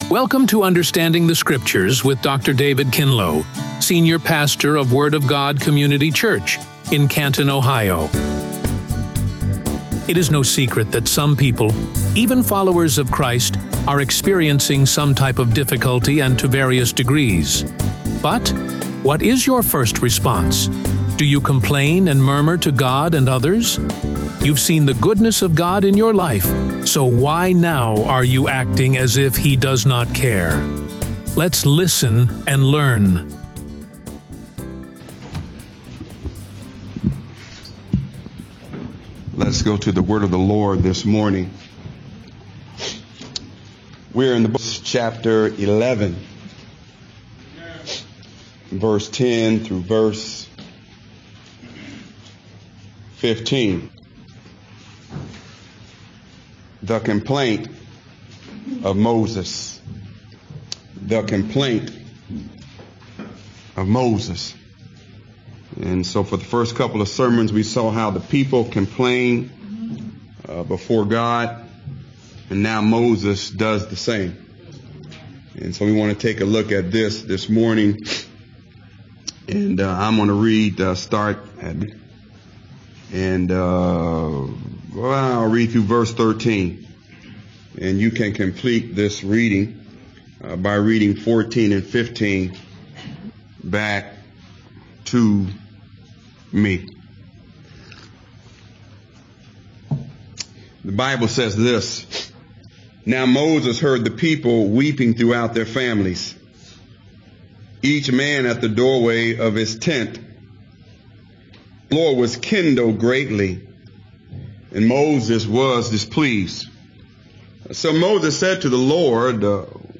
Radio Sermons | The Word of God Community Church
RADIO SERMON